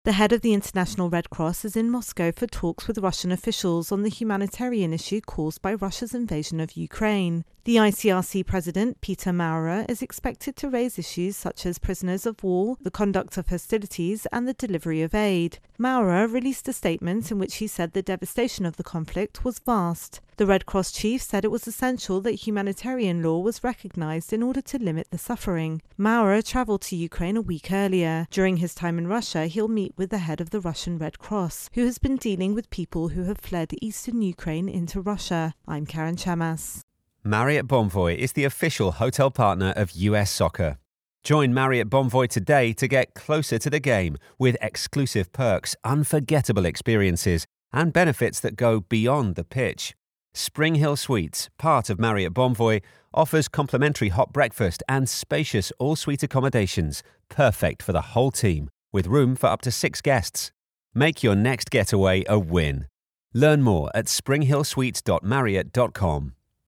Russia Ukraine War Red Cross Intro and Voicer